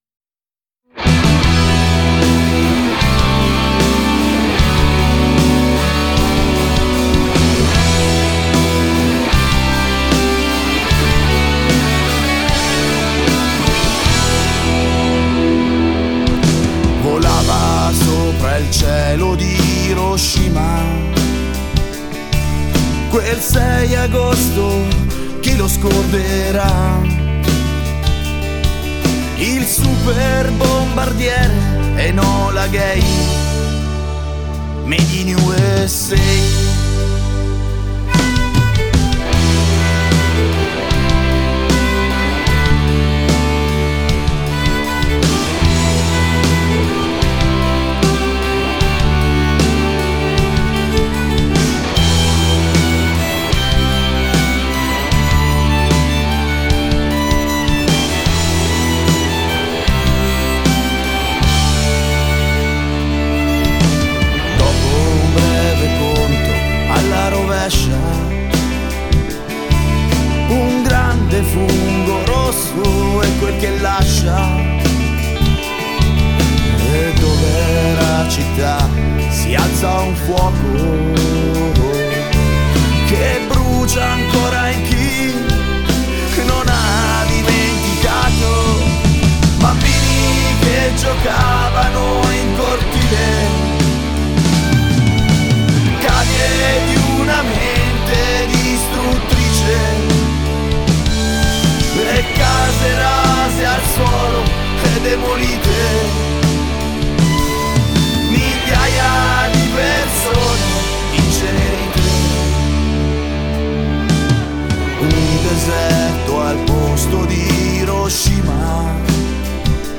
Rockszene
2024 neu eingespielt